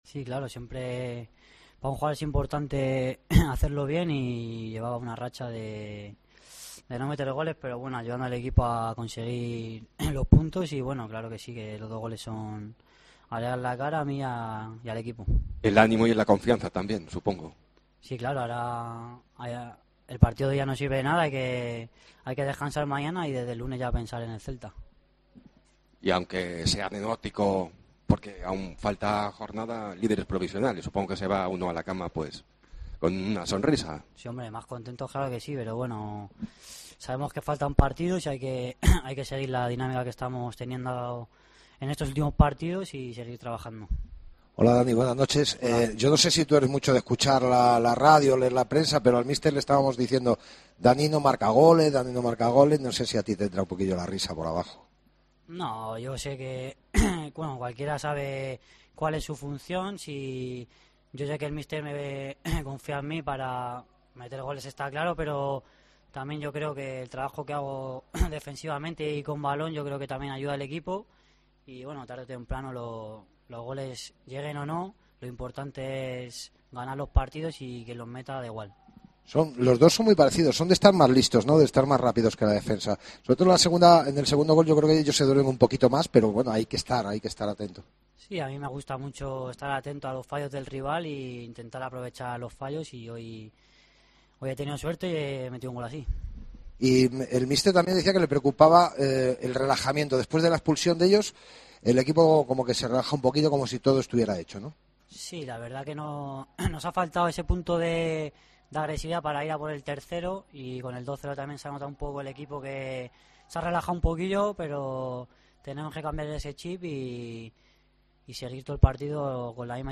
Escucha aquí a los dos jugadores de la Deportiva Ponferradina